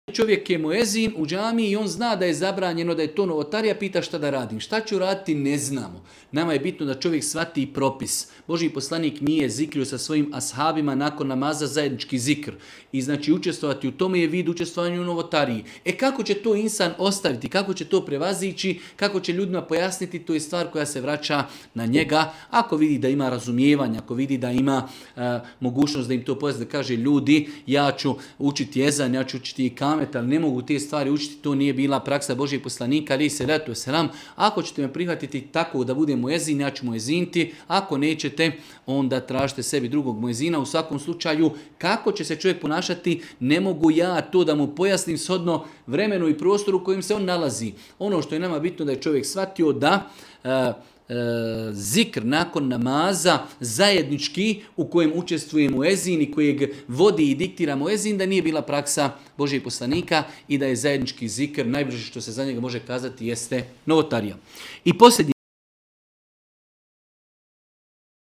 u video predavanju ispod.